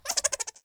Racoon_Laugh.wav